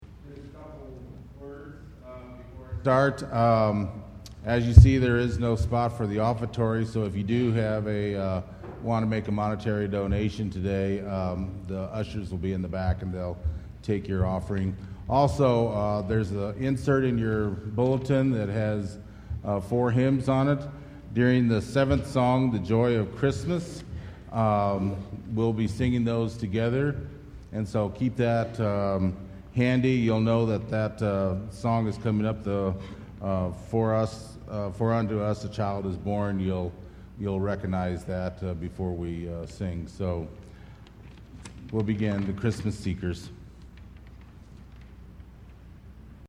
Sunday Service
Christmas Cantata
Christmas Cantata                  "Christmas Seekers"                      Burns Chancel Choir